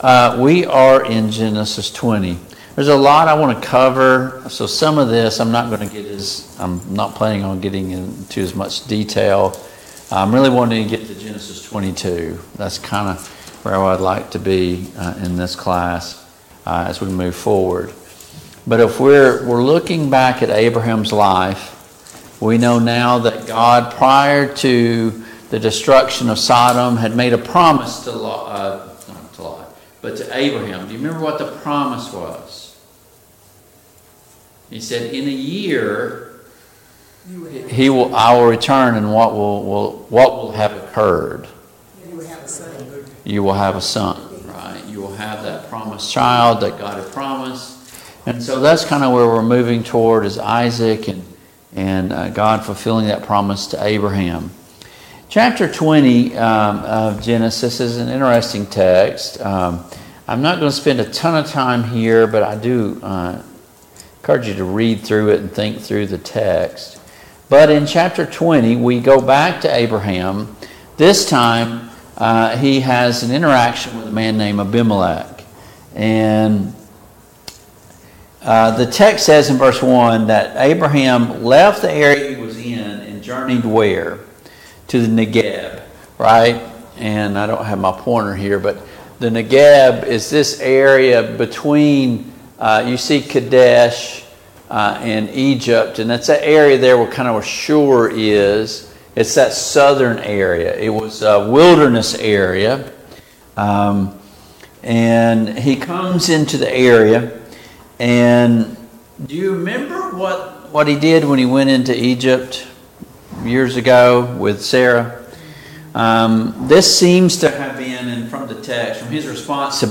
Study of Genesis Passage: Genesis 20, Genesis 21 Service Type: Family Bible Hour Topics